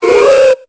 Cri de Hoothoot dans Pokémon Épée et Bouclier.